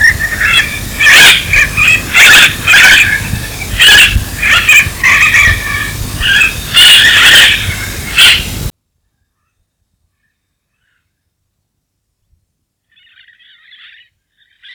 VOZ Repertorio variado. Las llamadas incluyen un neeep neeep penetrante fuerte, chill-scree-ah screed scree y regaños, raspando scraaah scraaah.
Voz más aguda que la guacamaya de hombros rojos.